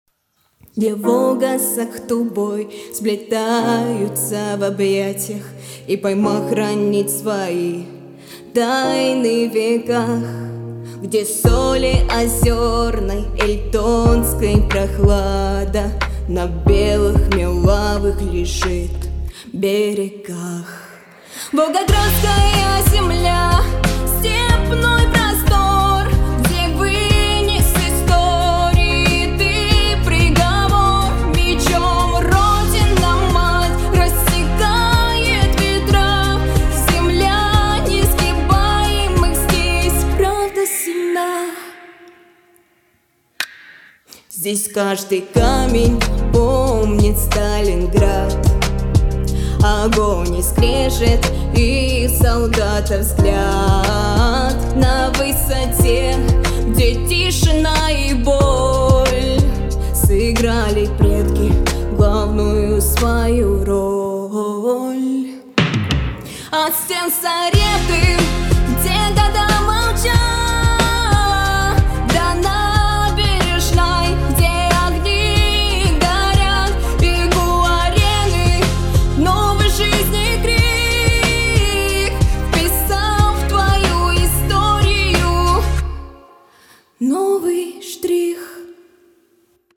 записала на музыкальной студии проникновенное стихотворение